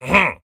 Minecraft Version Minecraft Version snapshot Latest Release | Latest Snapshot snapshot / assets / minecraft / sounds / mob / vindication_illager / idle5.ogg Compare With Compare With Latest Release | Latest Snapshot